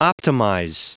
Prononciation du mot optimize en anglais (fichier audio)
Prononciation du mot : optimize